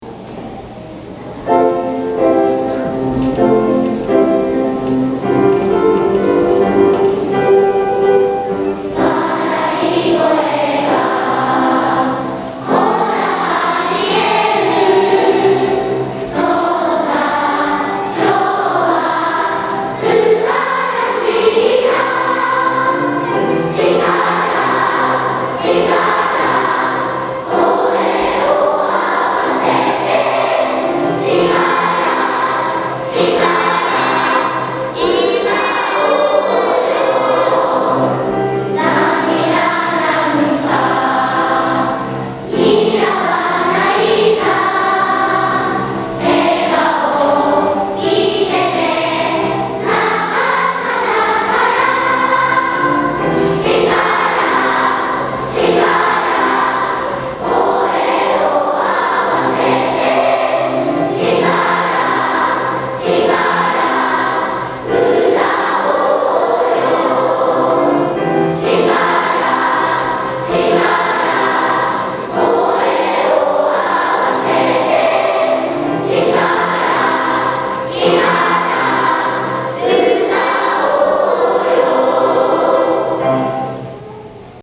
大空創立記念コンサート